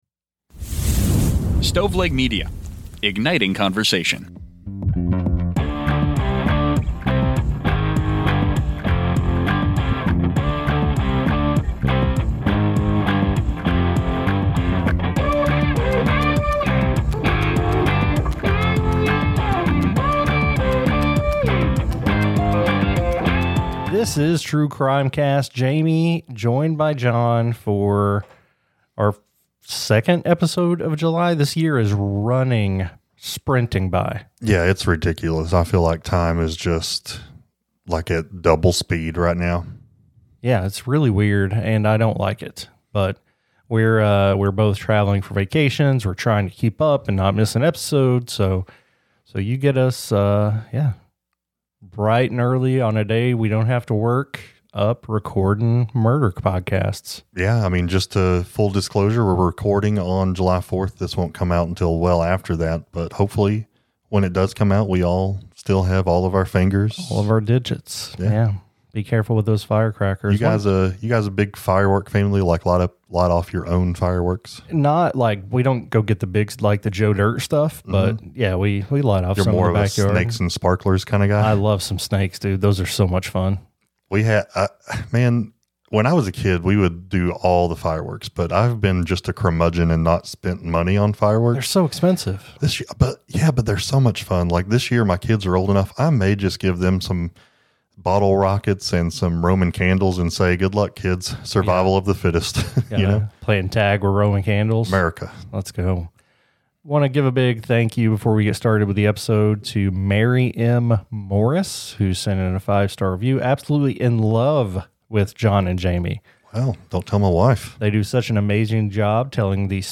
Documentary, Personal Journals, True Crime, Society & Culture